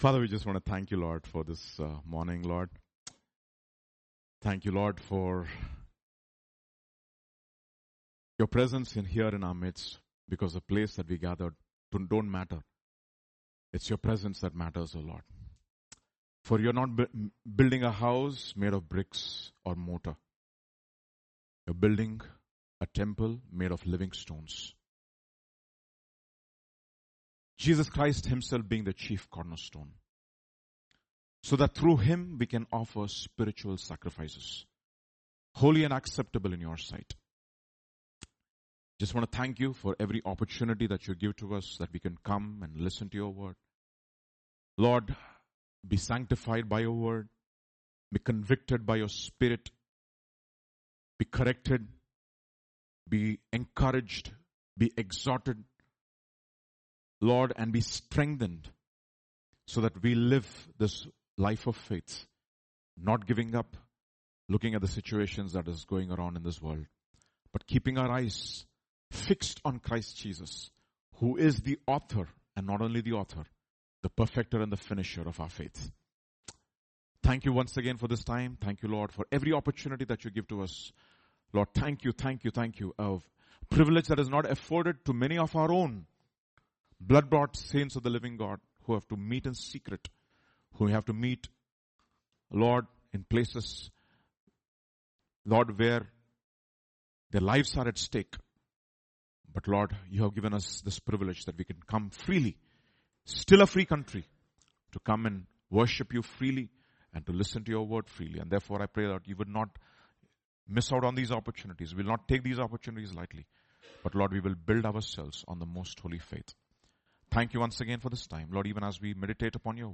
Sermon Preached on Sunday Morning Service.